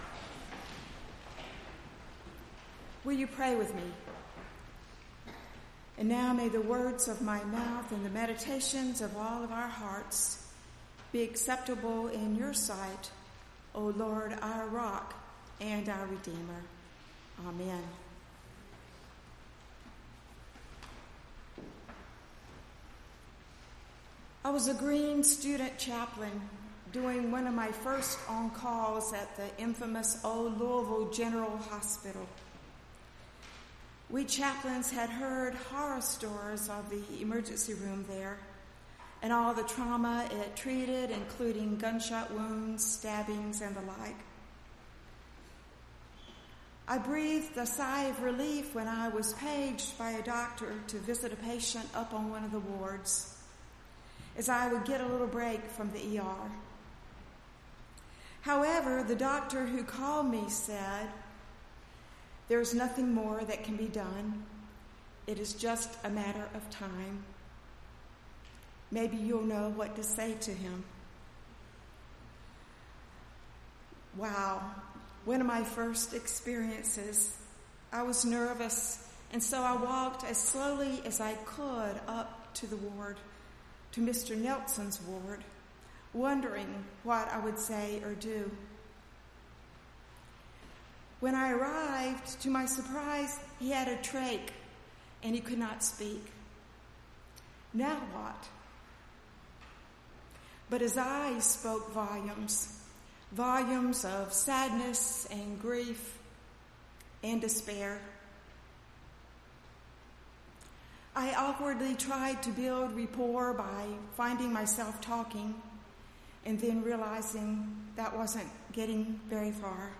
8-19-18-sermon.mp3